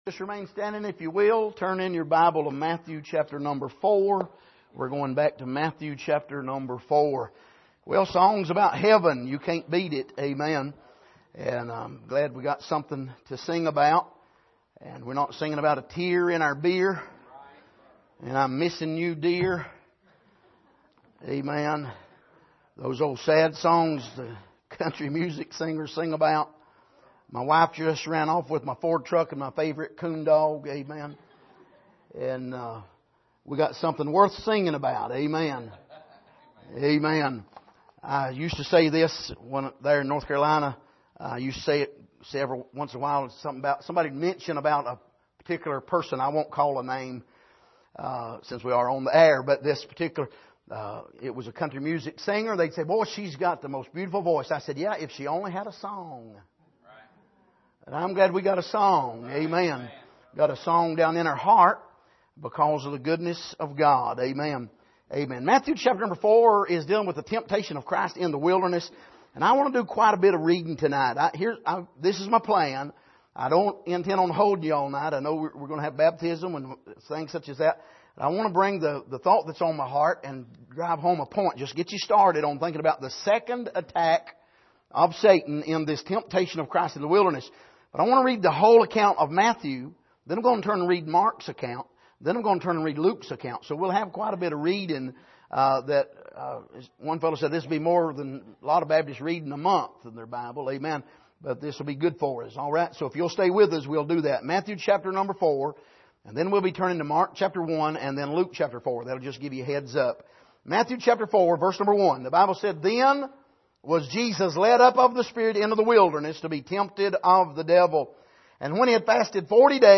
Passage: Matthew 4:1-11 Service: Sunday Evening